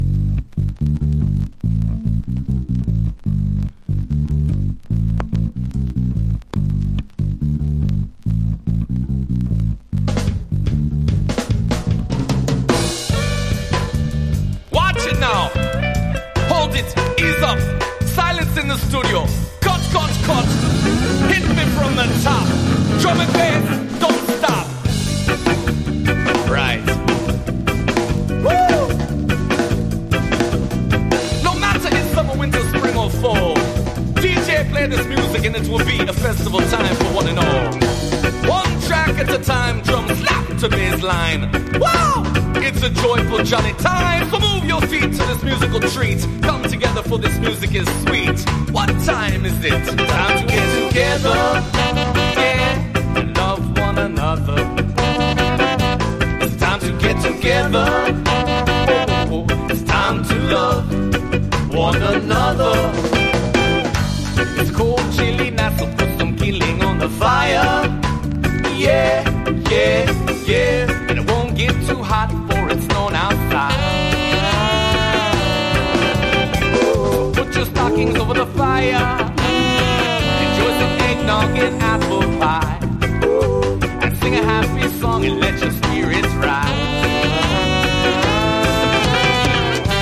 • REGGAE-SKA
# SKA / ROCK STEADY